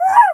crow_raven_call_02.wav